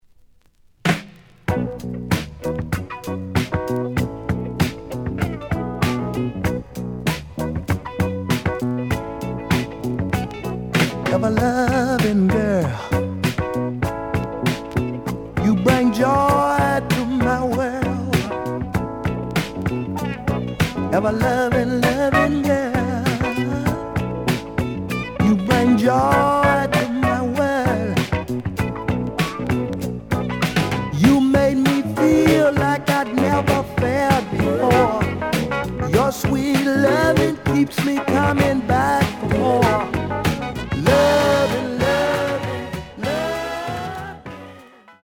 The audio sample is recorded from the actual item.
●Genre: Soul, 70's Soul
B side plays good.)